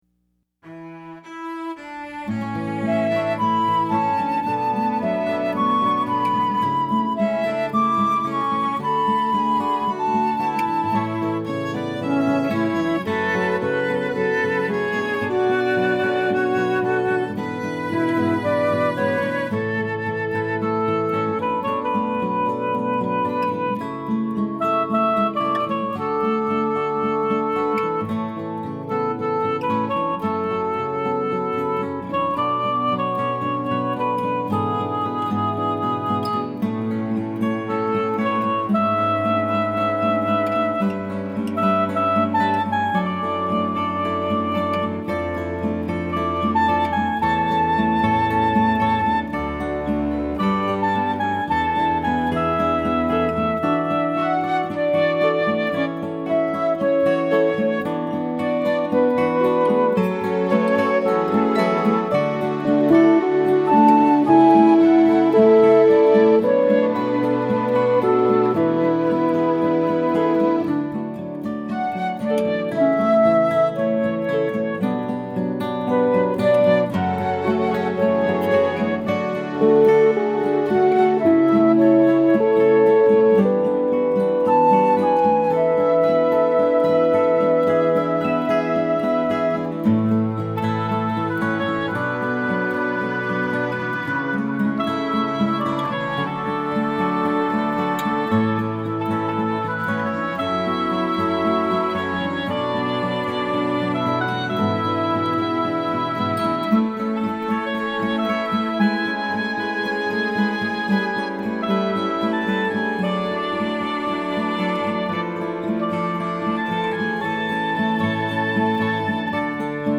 set-you-free-instrumental-3_19_12.mp3